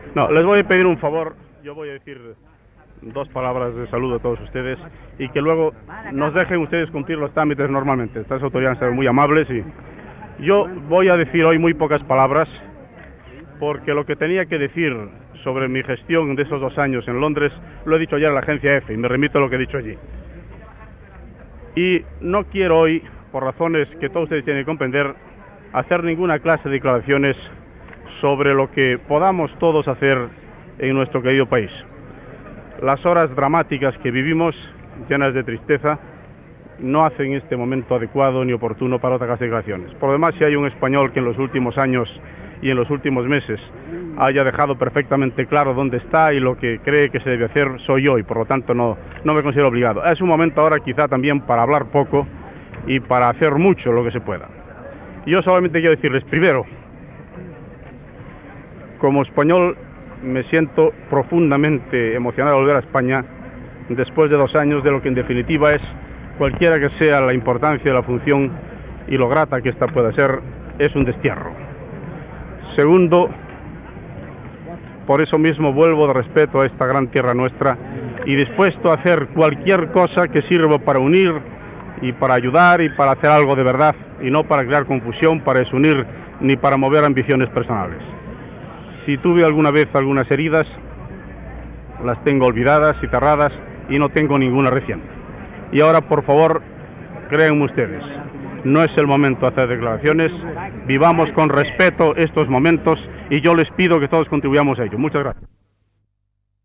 A l'aeroport de Barjas (Madrid), declaracions del fins aleshores ambaixador espanyol al Rege Unit, Manuel Fraga Iribarne, que torna a Espanya, poques hores abans de la mort del cap d'Estat Francisco Franco.
Informatiu